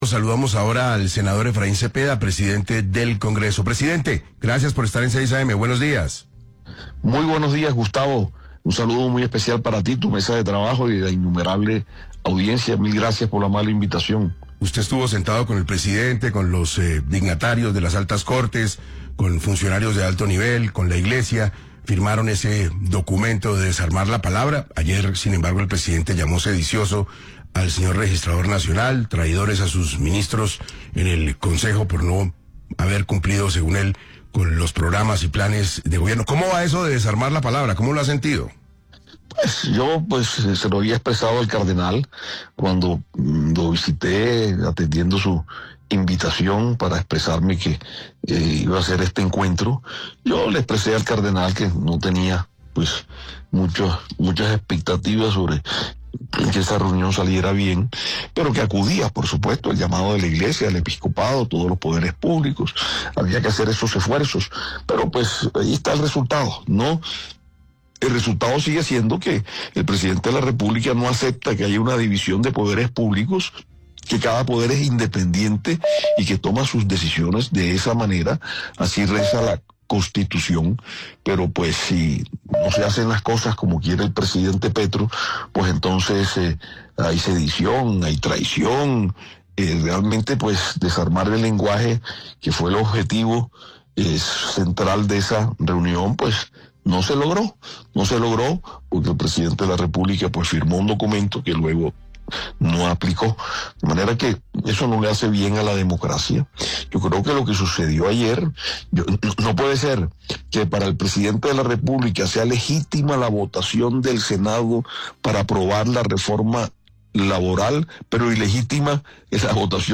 Tras el encuentro promovido por la Iglesia Católica colombiana el 16 de junio, el presidente del Congreso, Efraín Cepeda, aseguró en entrevista con 6AM de Caracol Radio que el presidente Gustavo Petro no ha honrado el compromiso firmado durante ese almuerzo de alto nivel que buscaba “desarmar la palabra” y calmar las tensiones entre poderes públicos.